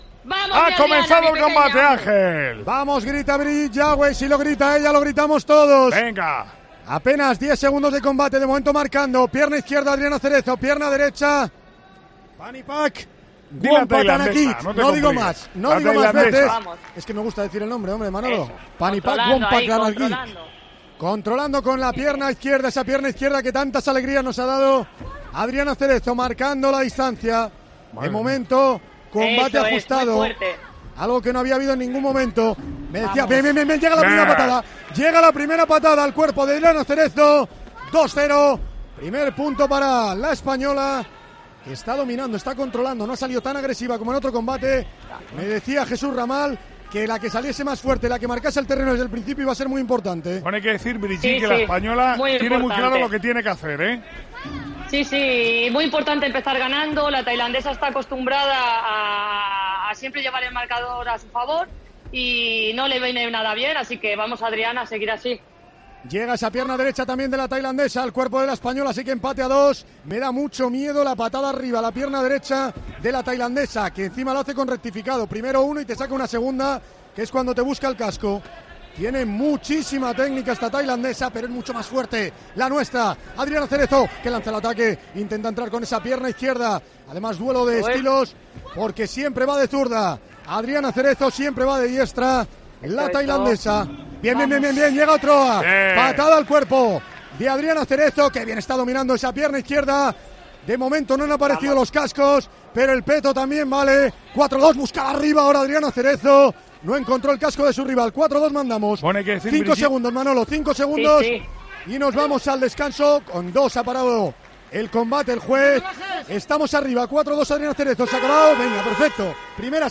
Escucha la narración en la Cadena COPE del combate por la medalla de oro de la categoría de -49 kilogramos de taekwondo entre Adriana Cerezo y la...